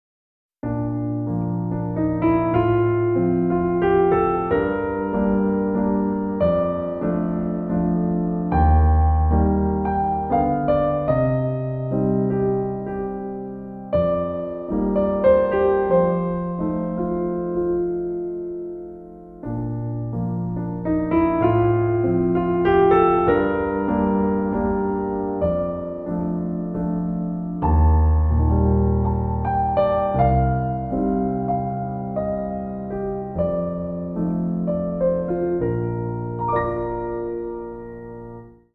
mp3Merikanto, Oskar, Valse lente, Op.33, mm.1-16